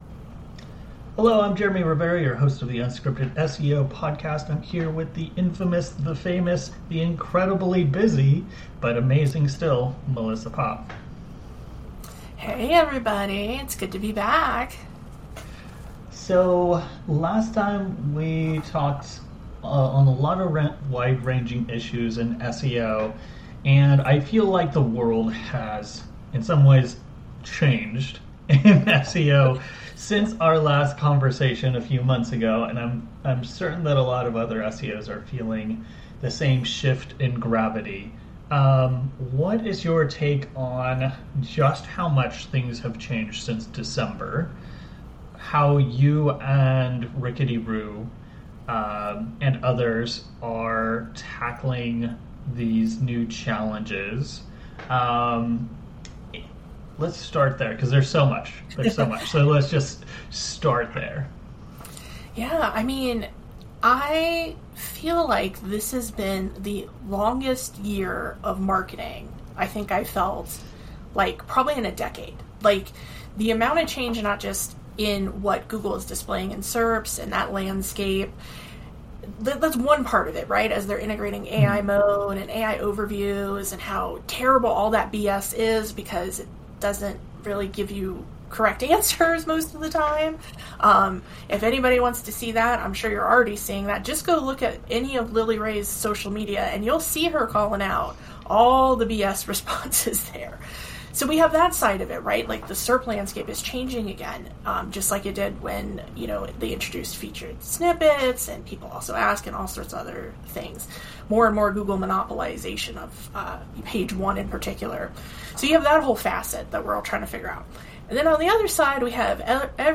100% unscripted, 100% unrehearsed, 100% unedited, and 100% real.